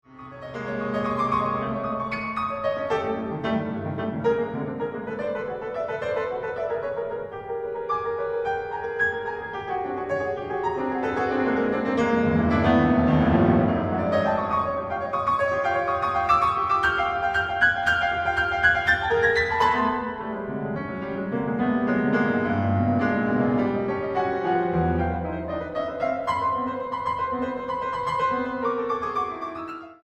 pianista
Música Mexicana para Piano